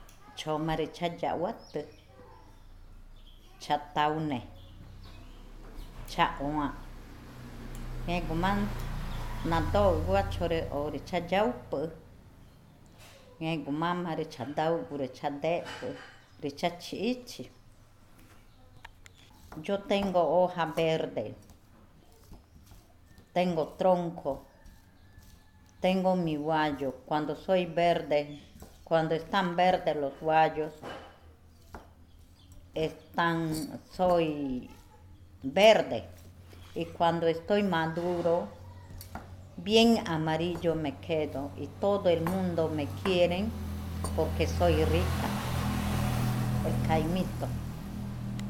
Cushillococha